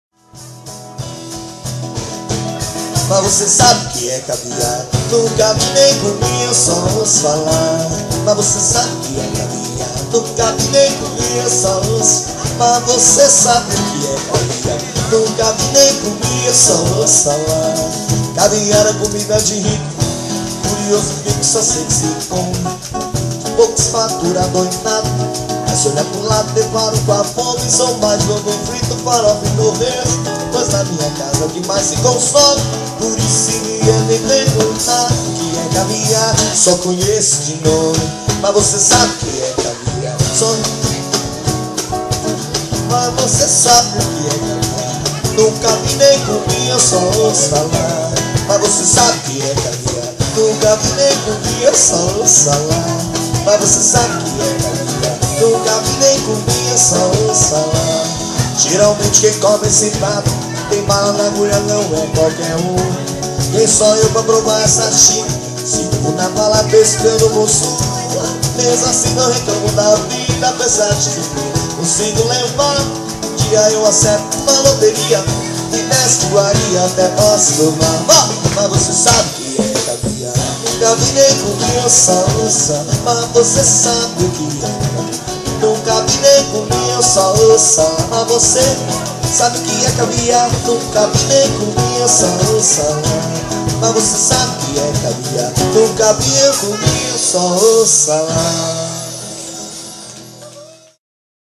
Voz e Violão Curitiba.